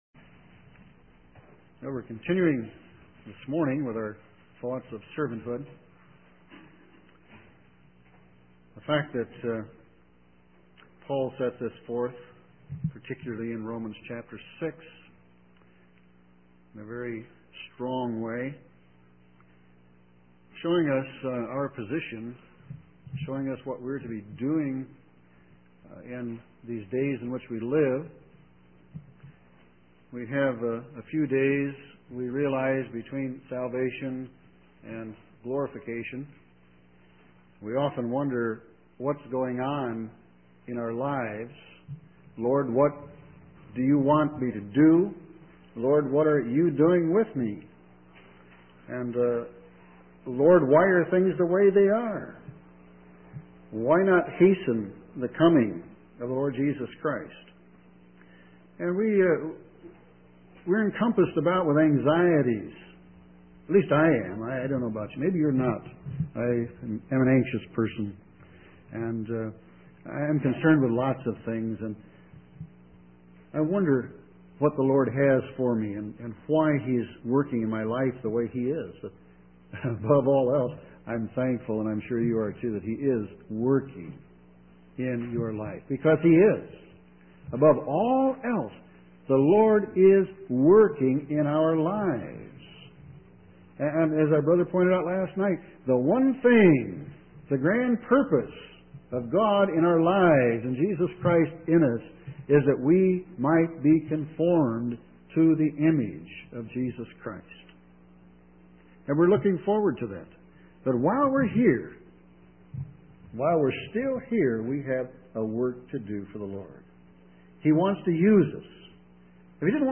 In this sermon, the speaker emphasizes the importance of counting ourselves in Christ and identifying with Him in our new life. He highlights the fact that God provides for us in amazing ways, even when we feel the need to be in control.